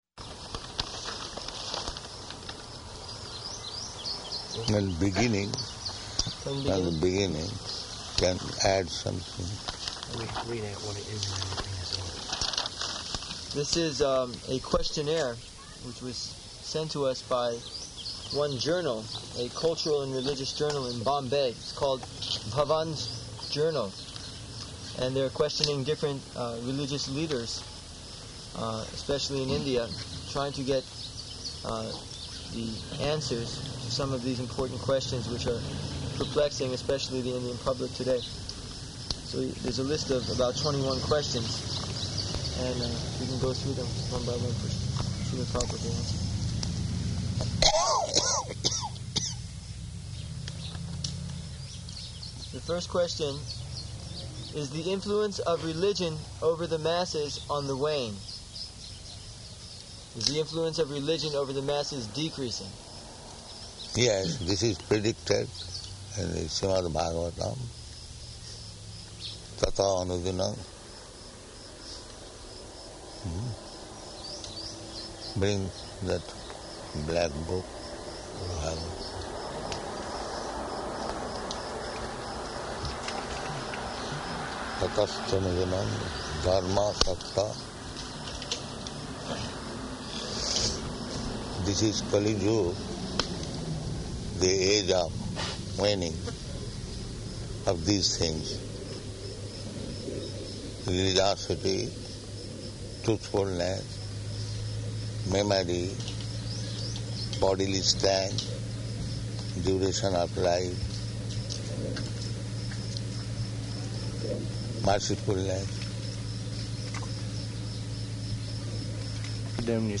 Bhavan's Journal, Answers to a Questionnaire 1 --:-- --:-- Type: Lectures and Addresses Dated: June 29th 1976 Location: New Vrindavan Audio file: 760629BJ.NV.mp3 Prabhupāda: Beginning.